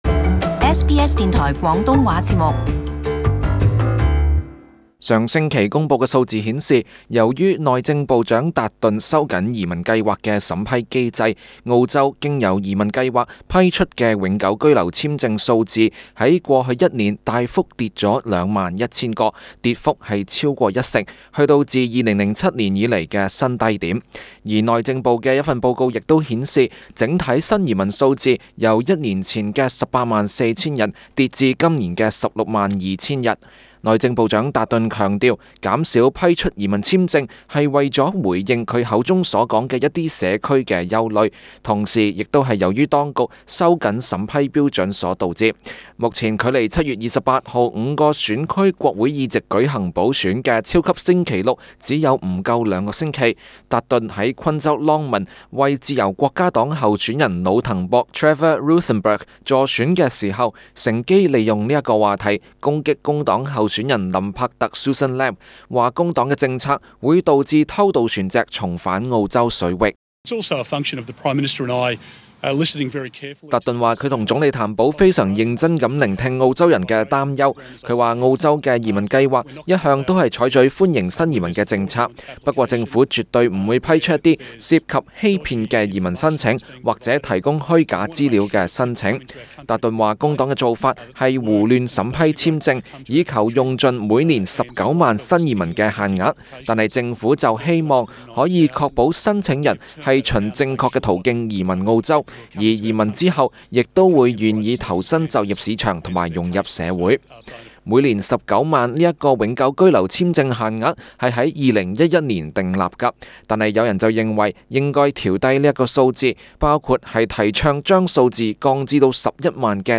【時事報導】商界：削減移民危害經濟